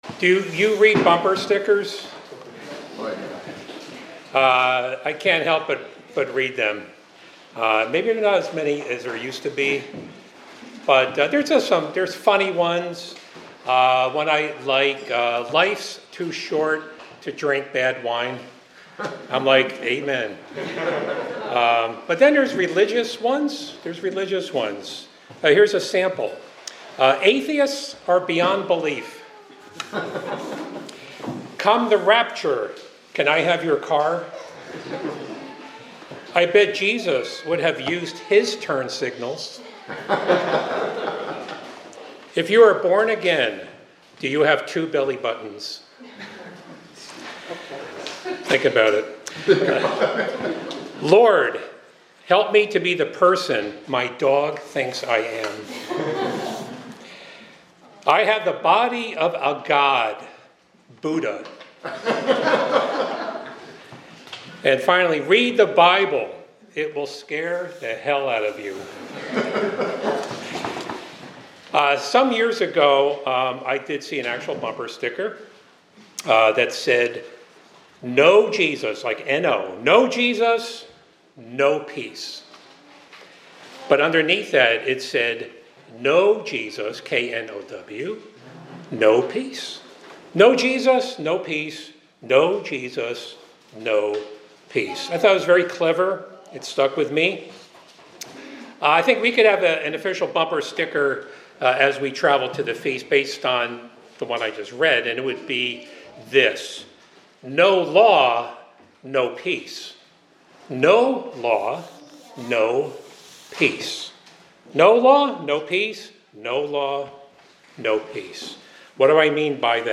This sermonette explores the deep connection between God's law and true peace, emphasizing that peace arises from obedience to divine commandments. It reflects on biblical scriptures to highlight how adherence to God's law will bring harmony and righteousness in the coming millennium under Christ's reign.
Given in Hartford, CT